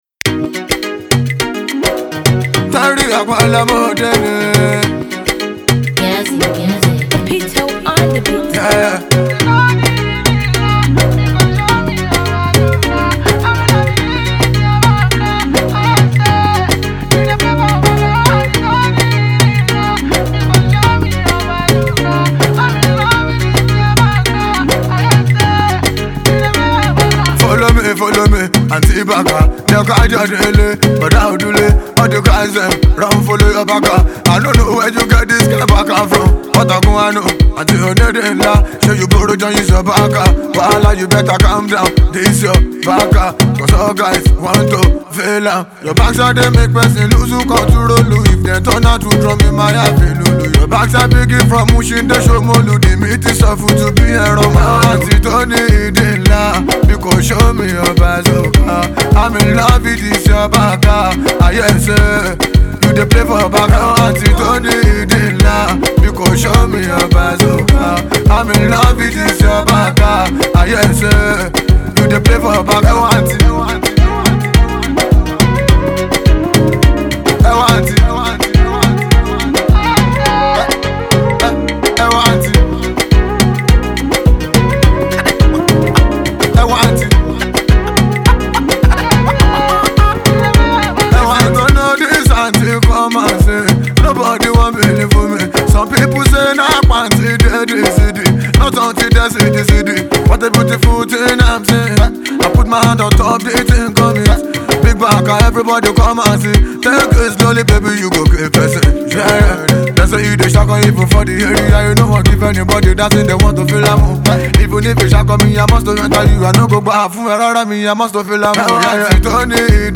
Afro-pop
a Caribbean influenced Afro-pop song with a hip-hop core